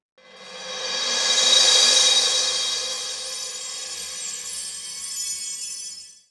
Cosmic Bells.wav